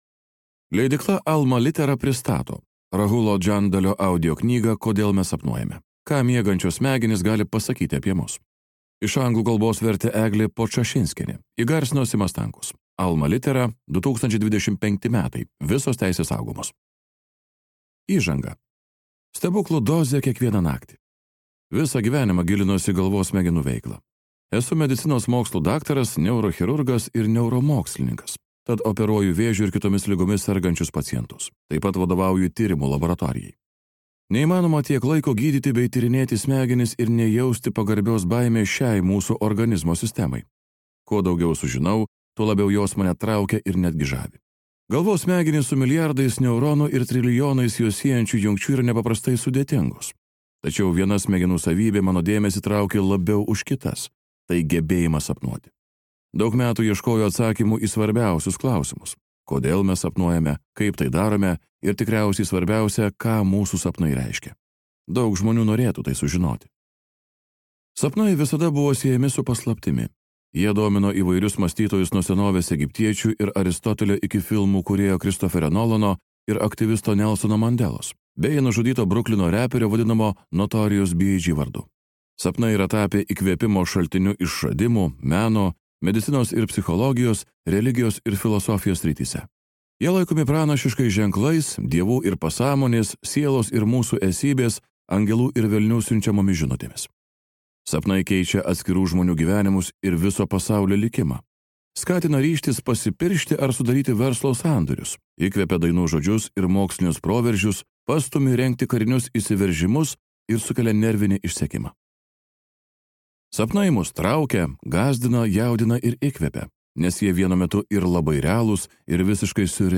Kodėl mes sapnuojame | Audioknygos | baltos lankos